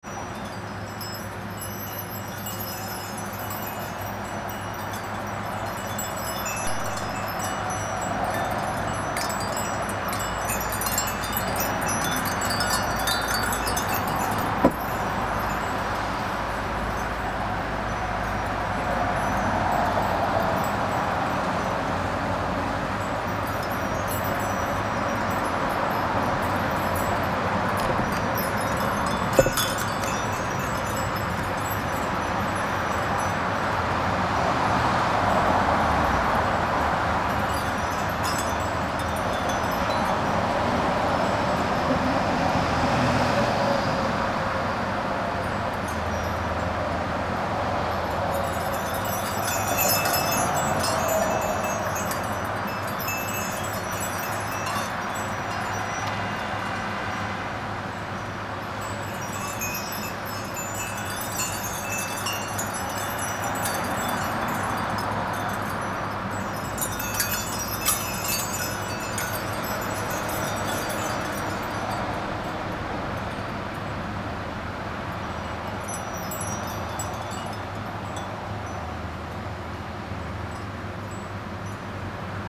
Anyway, I woke up and went out back again for the morning ringing and
It was relatively peaceful out there in spite of the traffic and I was grateful that I was awake at that hour- even if only for a moment.